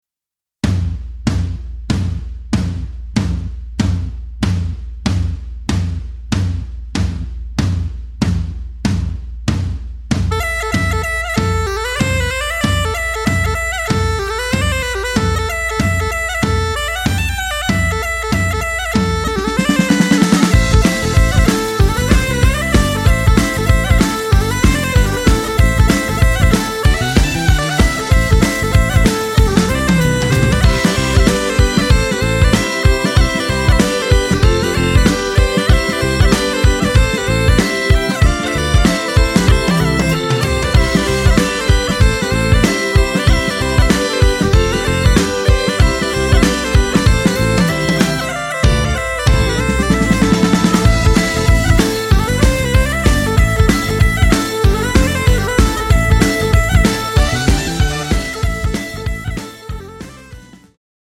Il Gruppo di musica folk & celtica